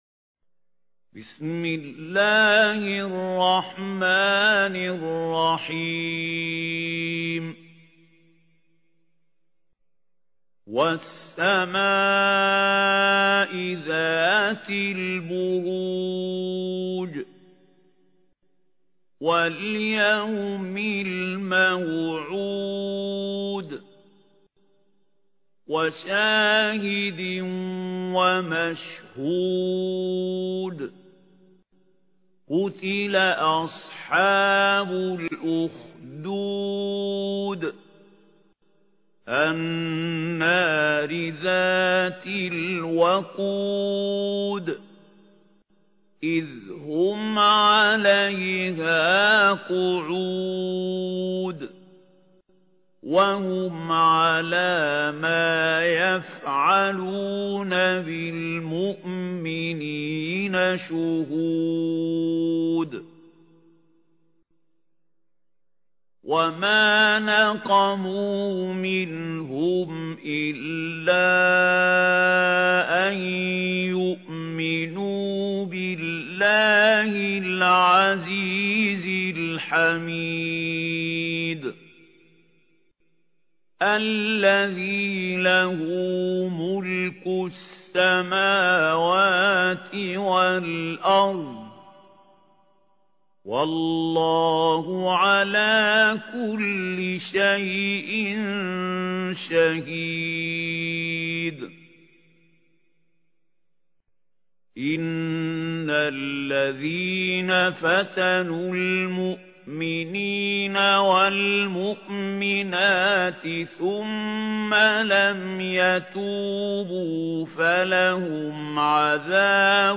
ترتيل
سورة البروج الخطیب: المقريء محمود خليل الحصري المدة الزمنية: 00:00:00